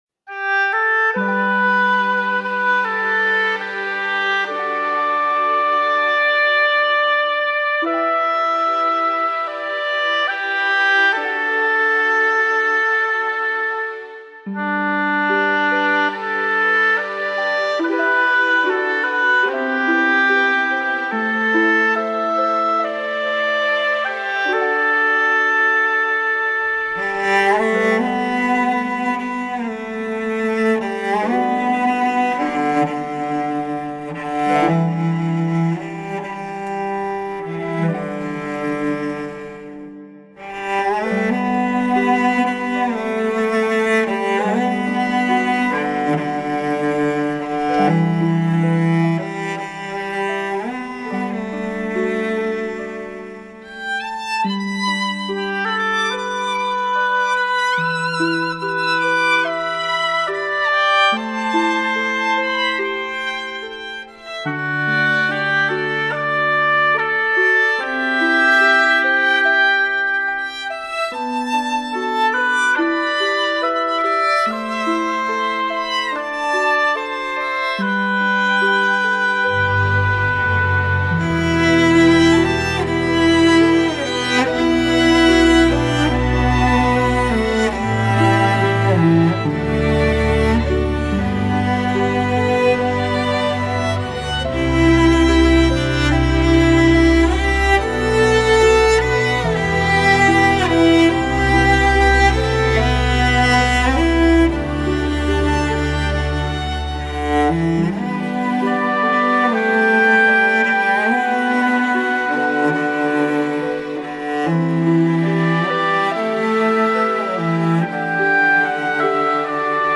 特以西方管弦，替代中国丝竹！
依旧是东方神秘的荡气回肠！